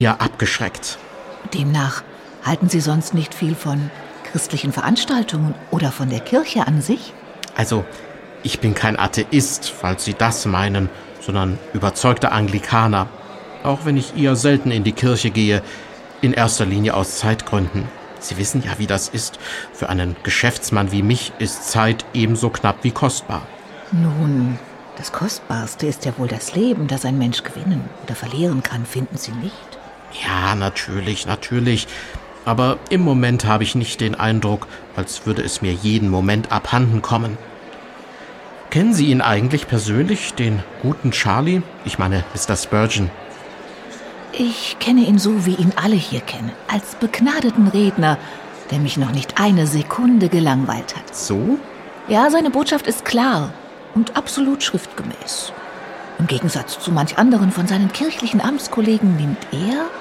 (MP3-Hörbuch - Download)